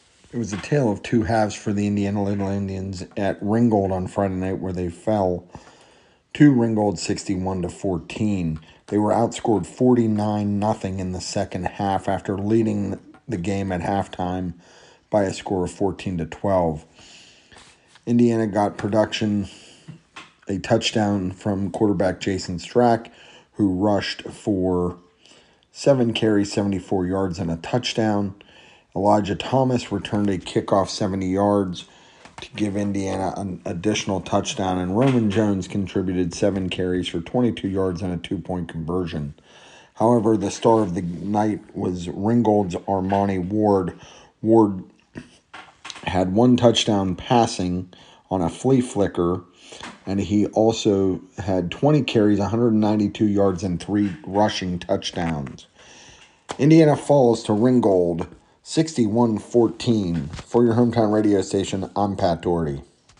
8-30-25-indiana-short-recap.mp3